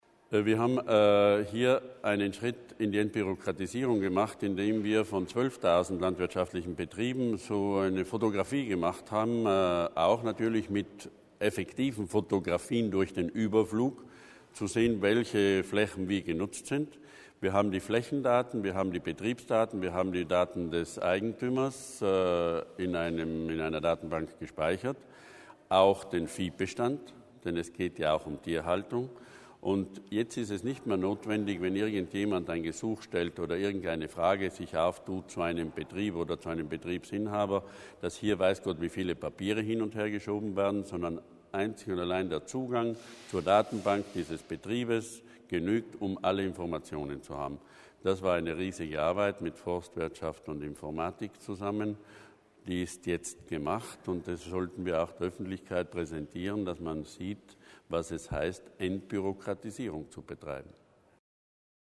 Landesrat Berger erklärt das Projekt LAFIS